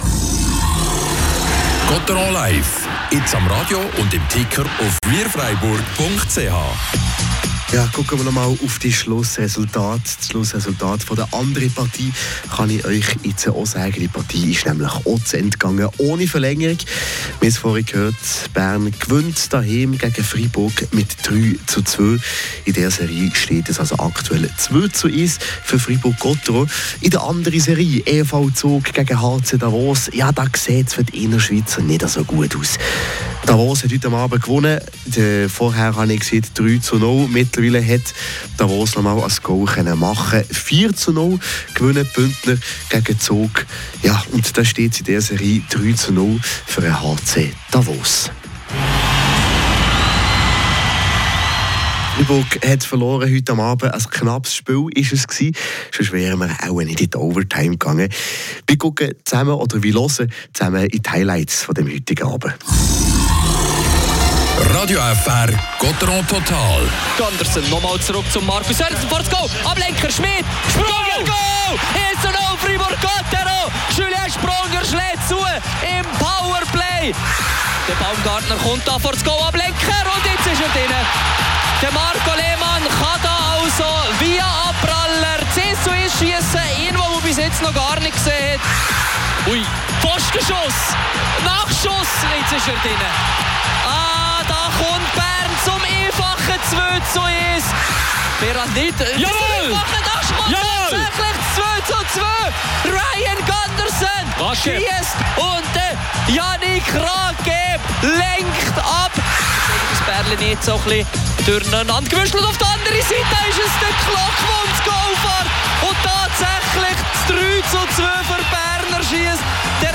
Der SC Bern gewinnt nach Verlängerung und verkürzt die Serie auf 1:2. Christoph Bertschy und Yannick Rathgeb geben Auskunft.